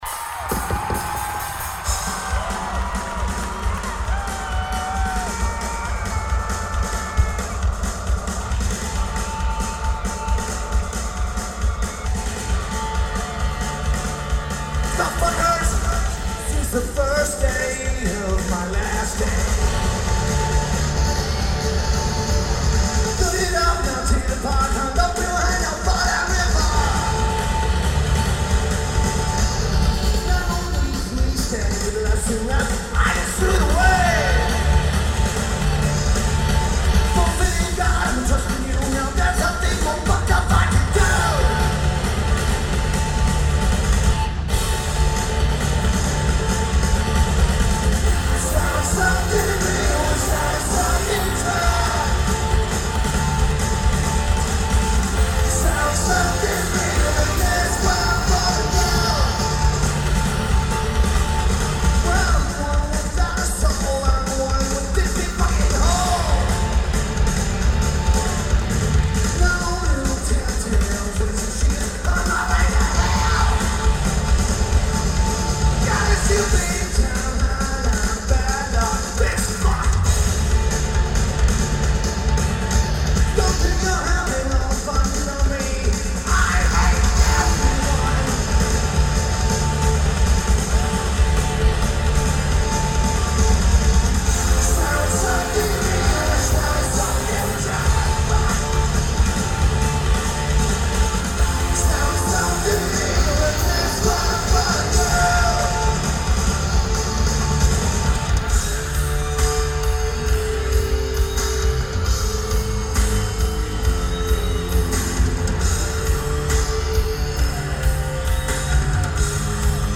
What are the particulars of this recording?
Tacoma Dome Lineage: Audio - AUD (Sonic Studio DSM-6 Mics + Sony TCD-D7)